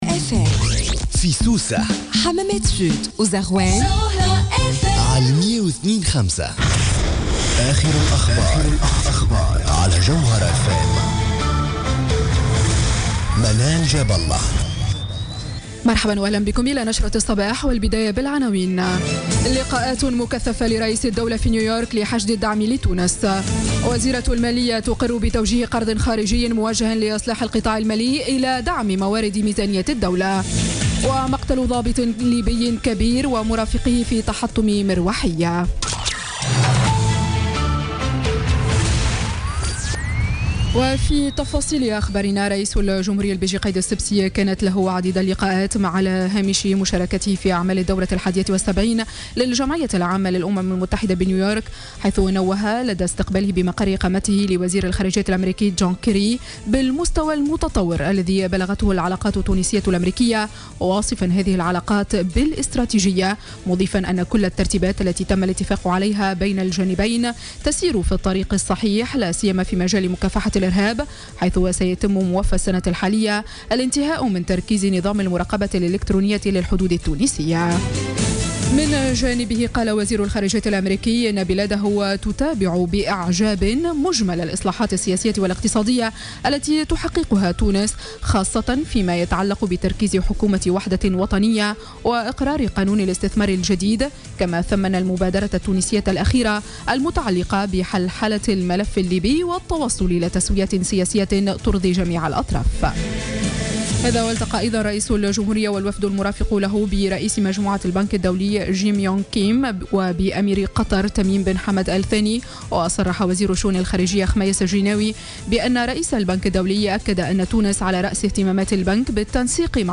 نشرة أخبار السابعة صباحا ليوم الثلاثاء 20 سبتمبر 2016